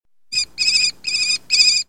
sparrow019.mp3